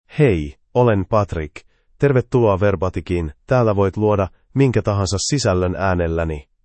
MaleFinnish (Finland)
Patrick — Male Finnish AI voice
Voice sample
Patrick delivers clear pronunciation with authentic Finland Finnish intonation, making your content sound professionally produced.